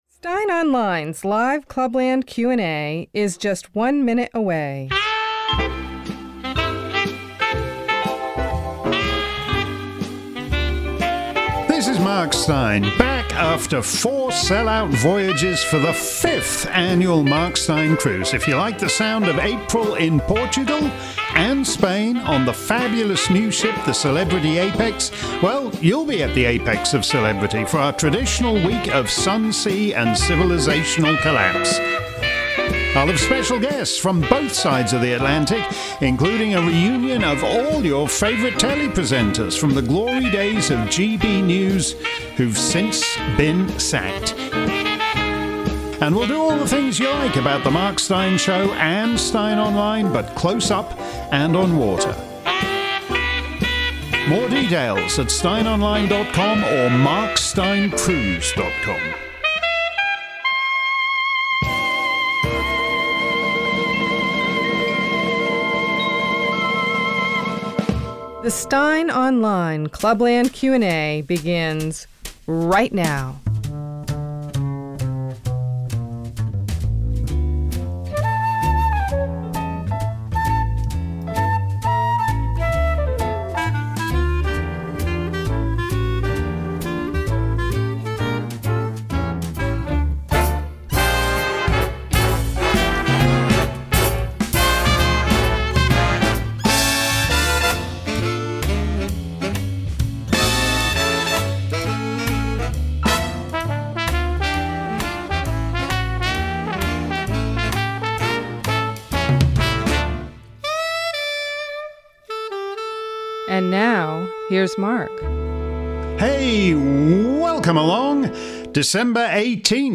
If you missed today's edition of Mark's Clubland Q&A live around the planet, here's the action replay. This week's show ran the gamut from US health care to South Korean fertility via Klaus Schwab penetrating your cabinet.